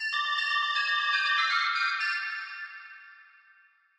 电话铃声 " Dreamer
描述：一种有趣的声音，可以作为智能手机的短信提醒。
标签： 邮件 打电话 电话 警报 铃声 文字 语气
声道立体声